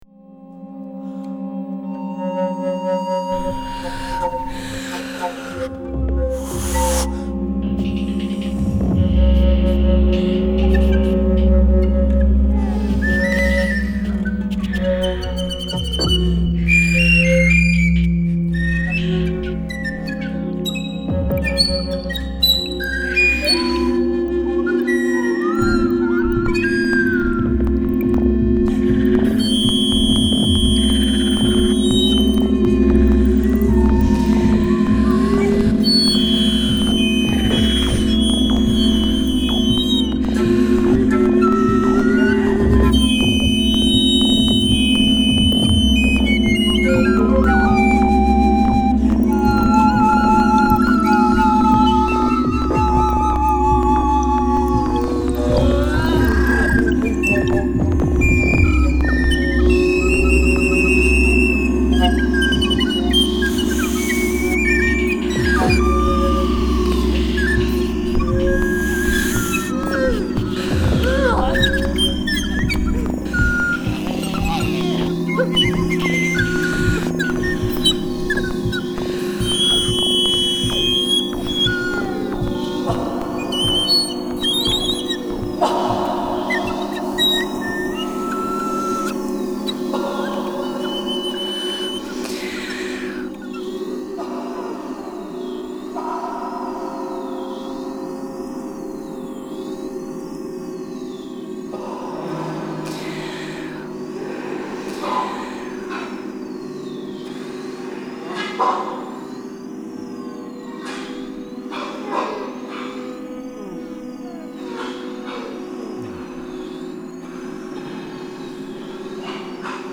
für 3 Blockflöten, 8-Kanal-Tonband und Liveelektronik